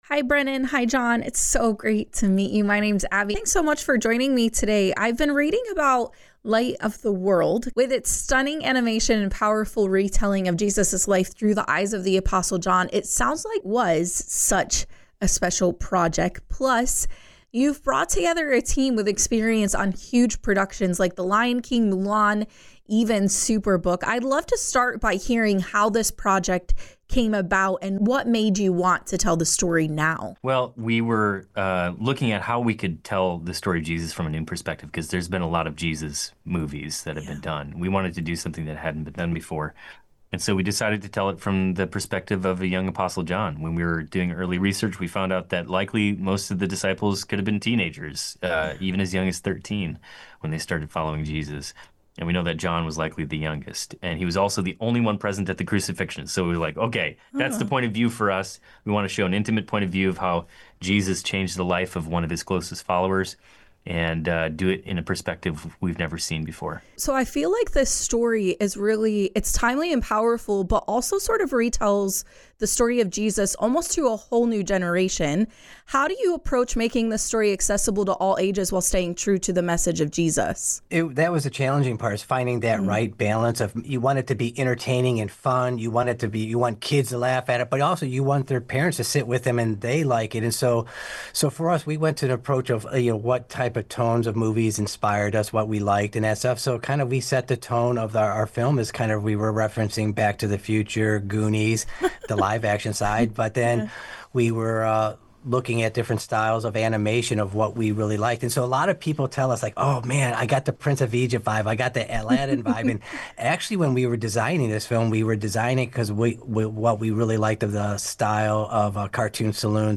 INTERVIEW ABOUT NEW FILM: LIGHT OF THE WORLD
Light-of-the-world-movie-interview-edited-and-website.mp3